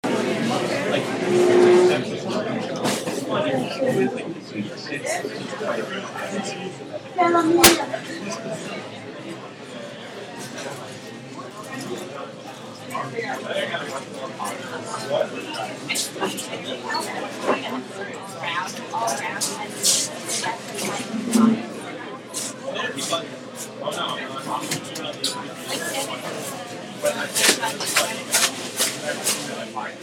This is at Au Bon Pain on March 14, about 1:15 in the afternoon. You can hear the sounds of music in the background, the ice machine, and me trying to put the cover on my drink.